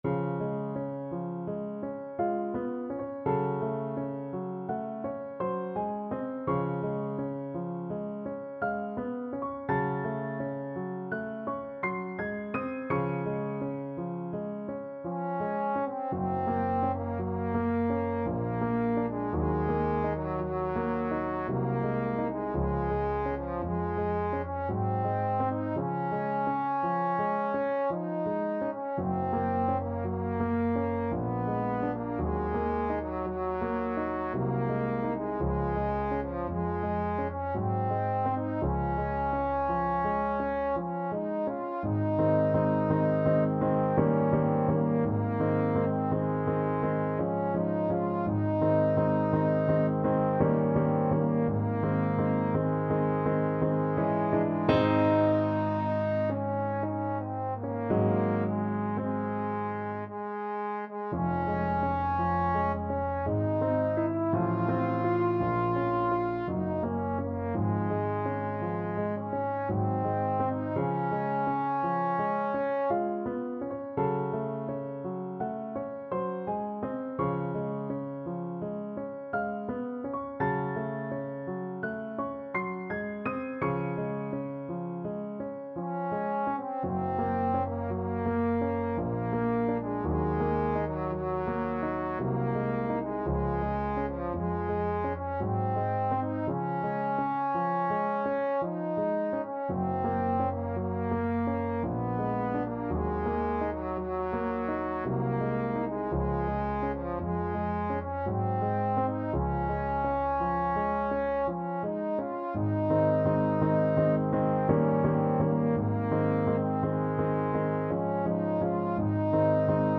Trombone
Db major (Sounding Pitch) (View more Db major Music for Trombone )
~ = 56 Ziemlich langsam
Classical (View more Classical Trombone Music)